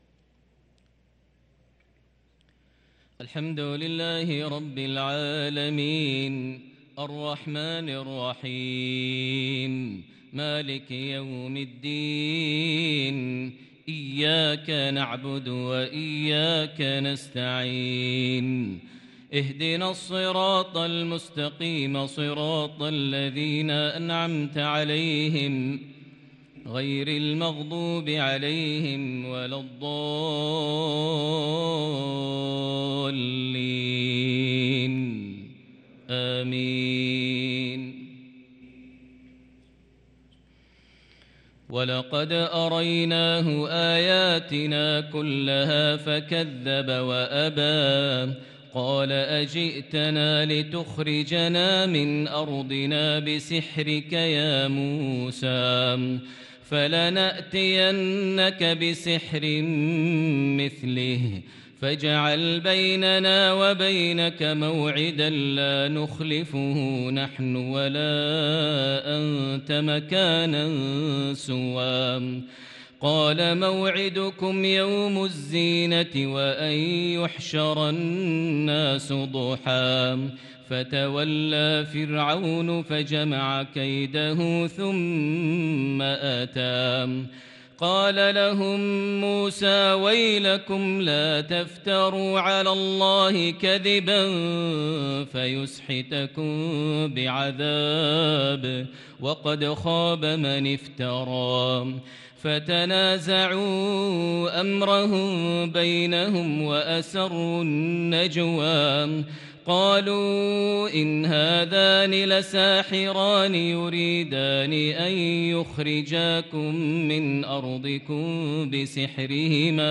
صلاة العشاء للقارئ ماهر المعيقلي 21 شعبان 1443 هـ
تِلَاوَات الْحَرَمَيْن .